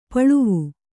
♪ paḷuvu